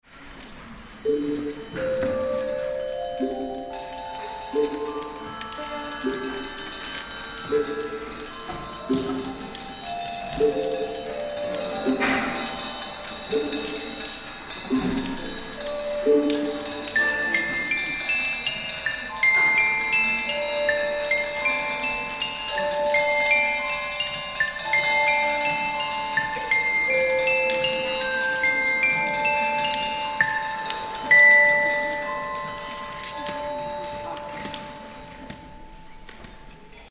Eine Aufführung der Klasse 3c
anhören, die wir zur Geschichte gesungen und gespielt haben!)